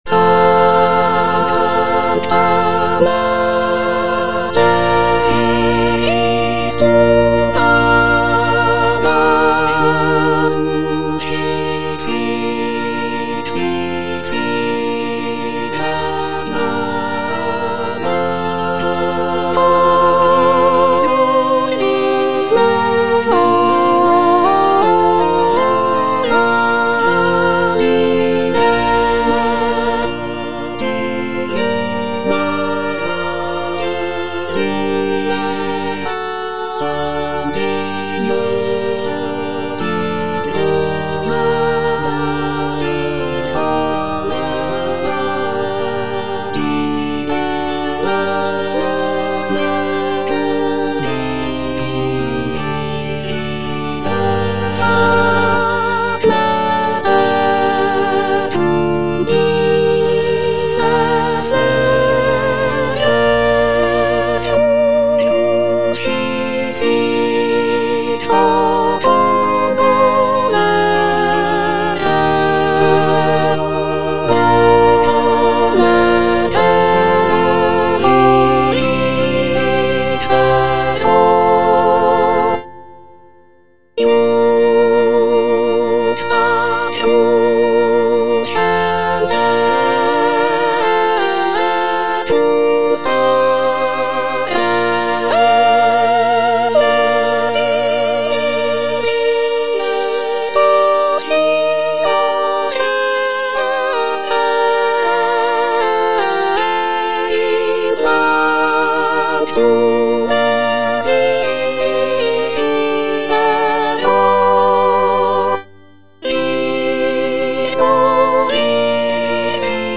G.P. DA PALESTRINA - STABAT MATER Sancta Mater, istud agas (soprano II) (Emphasised voice and other voices) Ads stop: auto-stop
sacred choral work
Composed in the late 16th century, Palestrina's setting of the Stabat Mater is known for its emotional depth, intricate polyphonic textures, and expressive harmonies.